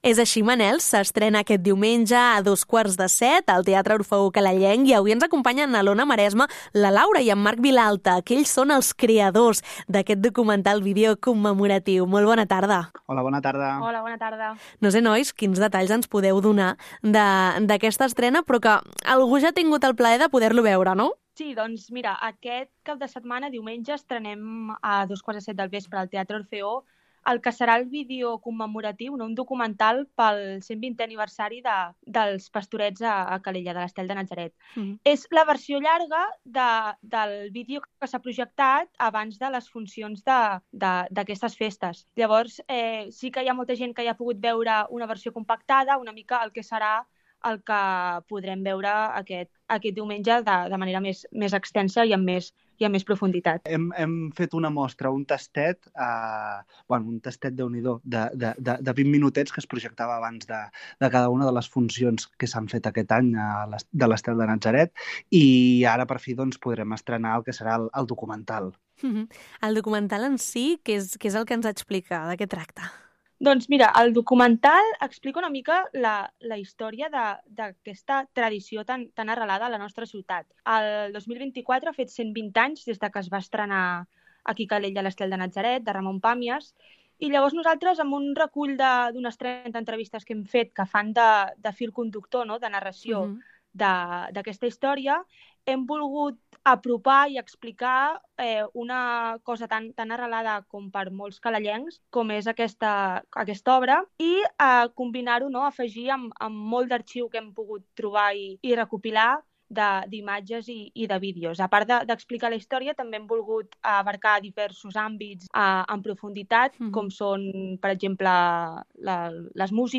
ENTREVISTA-PASTORETS-.mp3